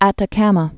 (ătə-kămə, ätä-kämä)